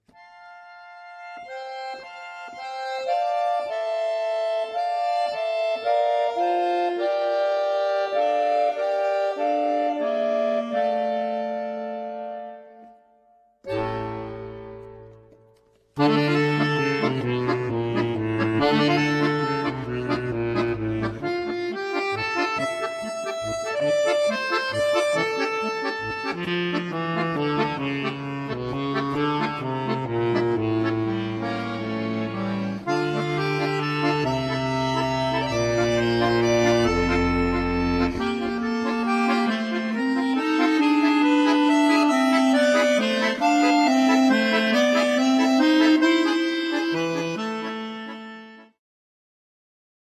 akordeon